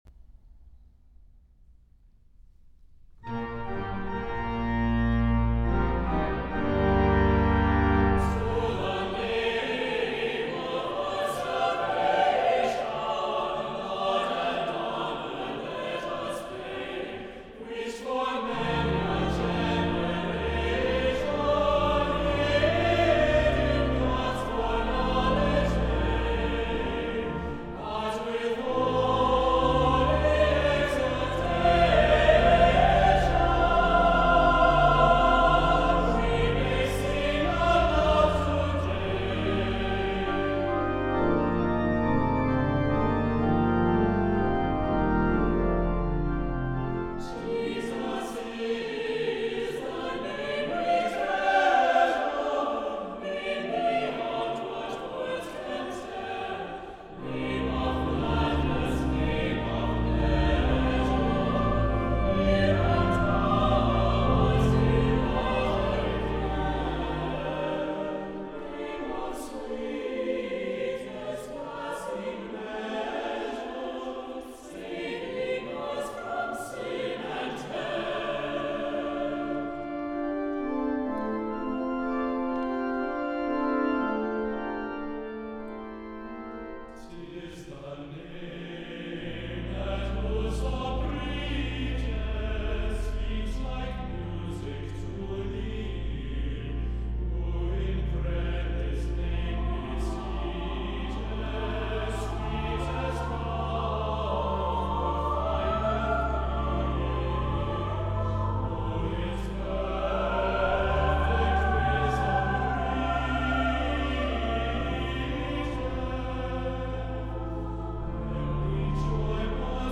energetic setting